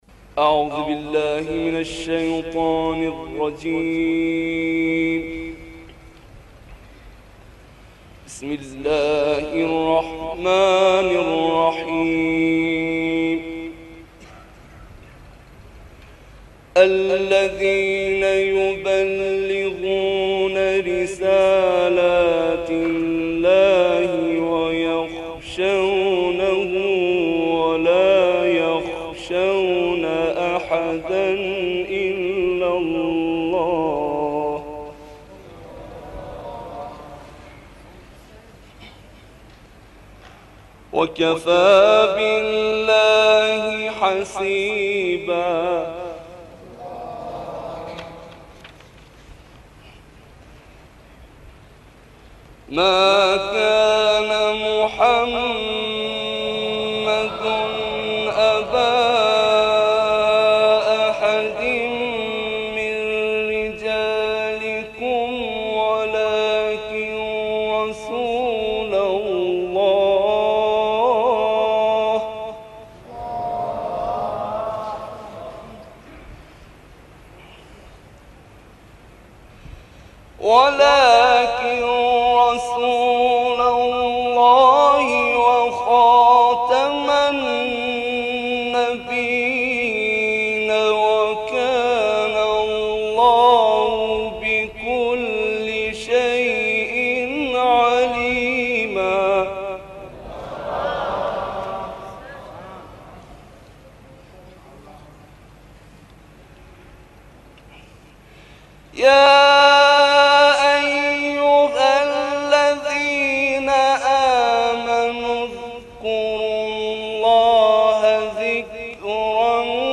برچسب ها: خبرگزاری قرآن ، ایکنا ، شبکه اجتماعی ، سال 1362 ، مسجد قنات آباد ، تلاوت قدیمی ، قرآن ، iqna